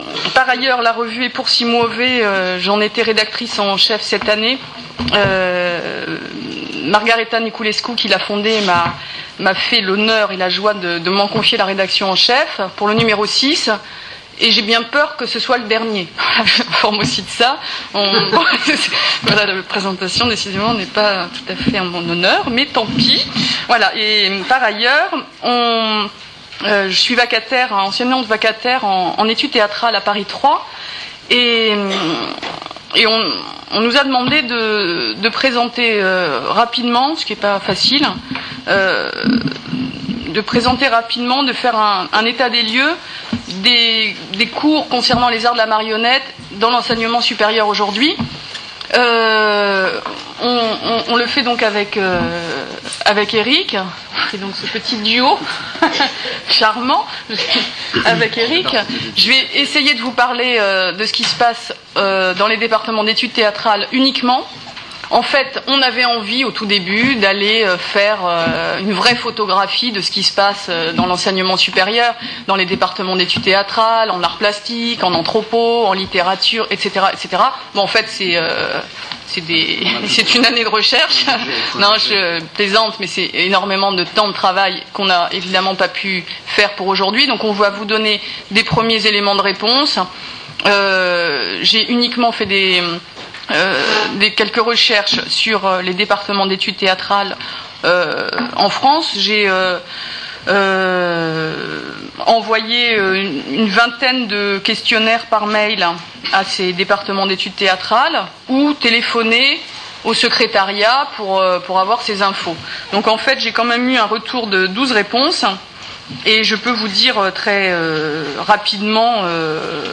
4 octobre 2008 | Bibliothèque Nationale de France | Paris Marionnette et chercheurs : état des lieux